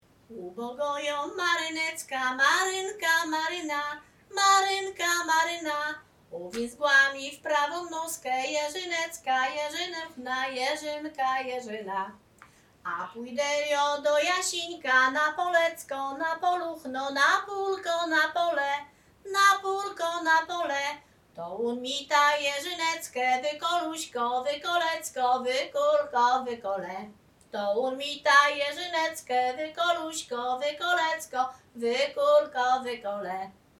województwo łódzkie, powiat sieradzki, gmina Sieradz, wieś Chojne
liryczne miłosne żartobliwe